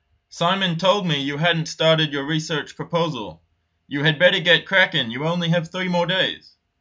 ネイティブによる発音はこちらです。